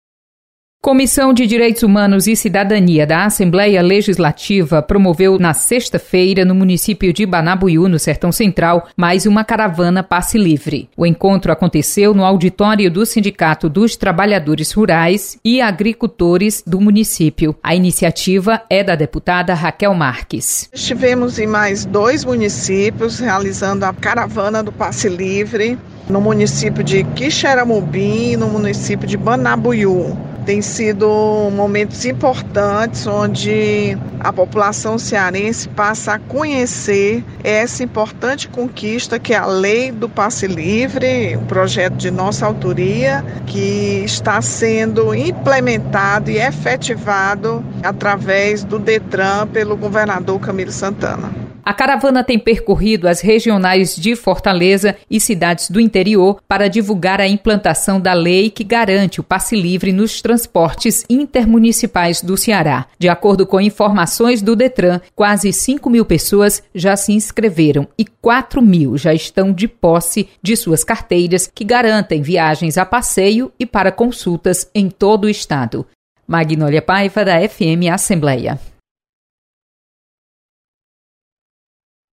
Comissão divulga Lei do Passe Livre no Sertão Central. Repórter